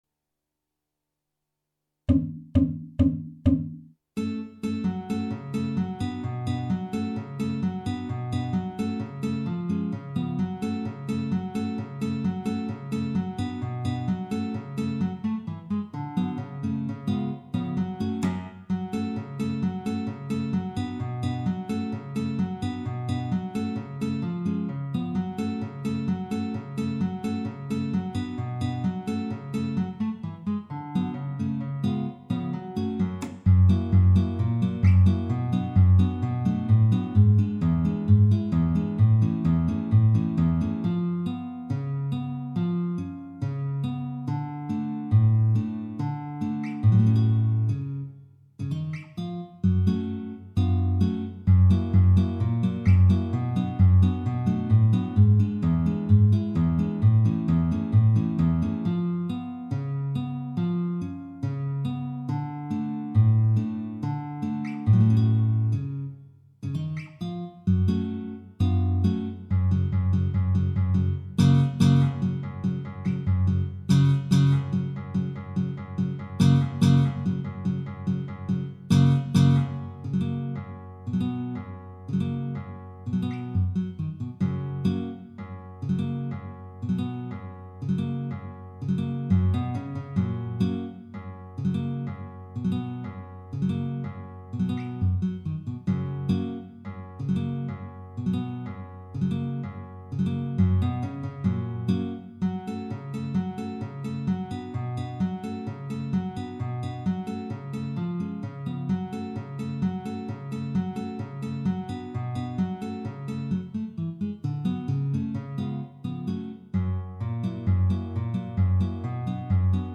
minus Guitar 1